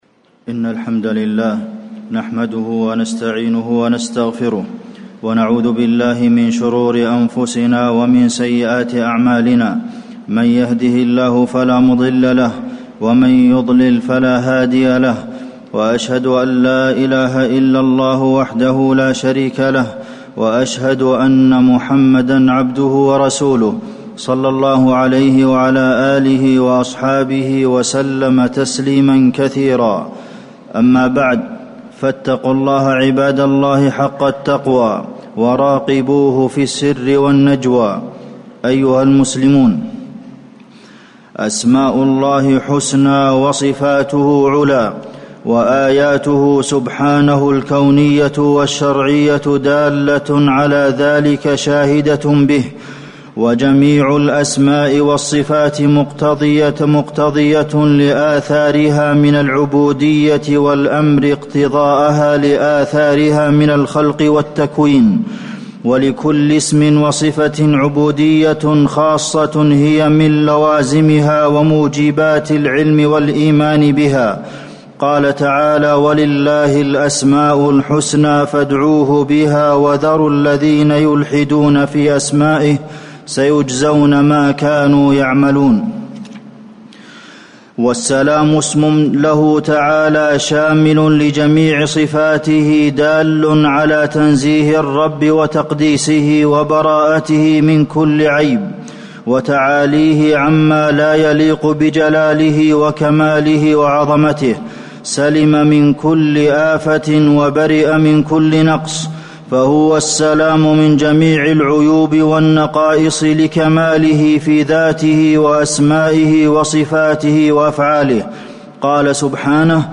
تاريخ النشر ٢٣ محرم ١٤٣٩ هـ المكان: المسجد النبوي الشيخ: فضيلة الشيخ د. عبدالمحسن بن محمد القاسم فضيلة الشيخ د. عبدالمحسن بن محمد القاسم اسم الله السلام